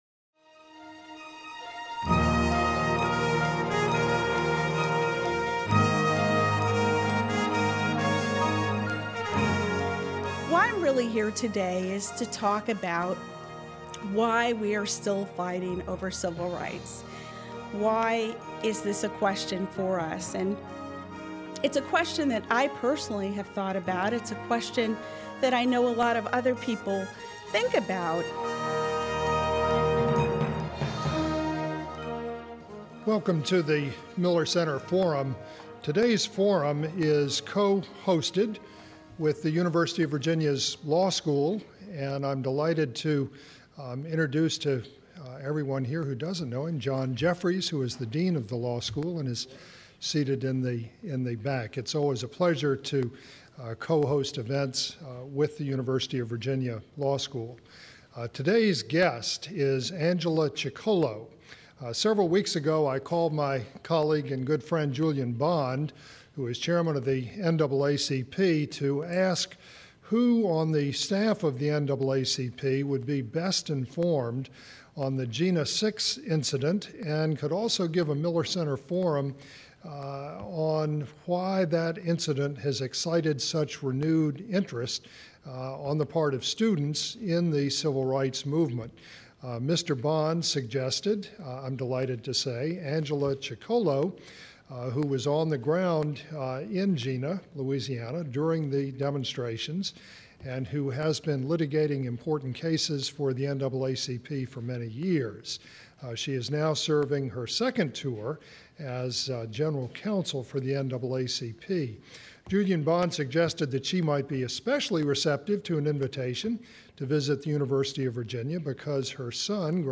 This Forum was co-hosted by the University of Virginia Law School.